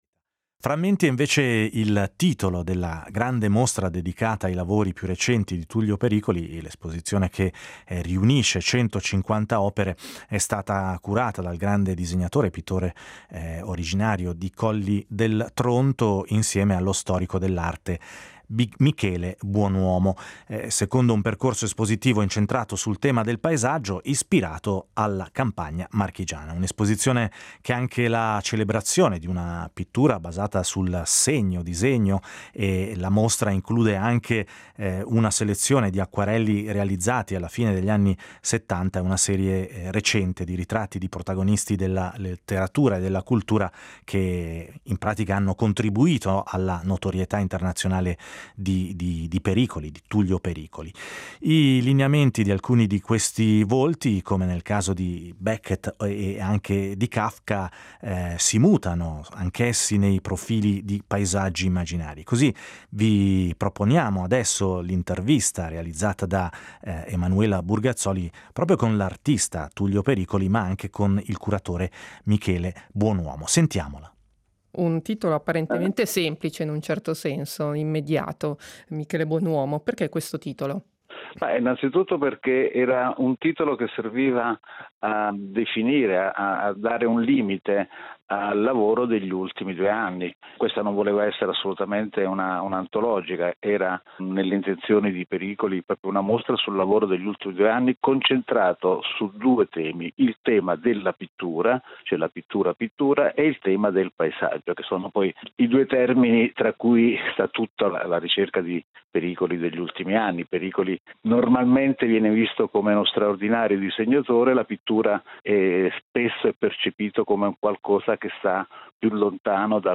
ha intervistato Tullio Pericoli